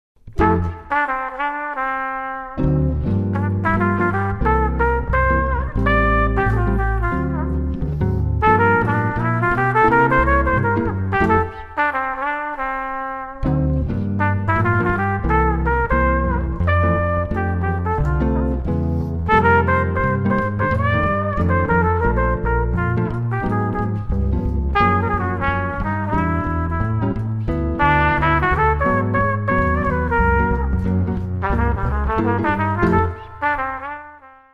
Manhattan Jazz Trio plays cool sophisticated jazz.
warm trumpet
double bass
This trio line-up is excellent for unobtrusive background jazz during cocktails, wedding receptions, dinner jazz and, dancing.